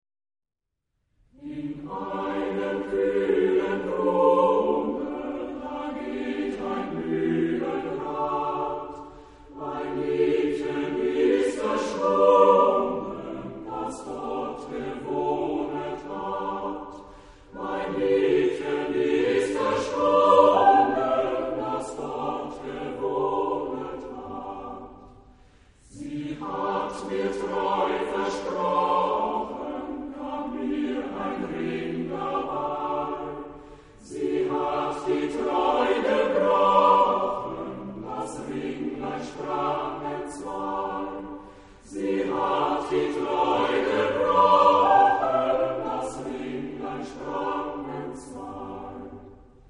Genre-Style-Forme : Arrangement choral ; Profane
Type de choeur : SATB  (4 voix mixtes )
Tonalité : sol majeur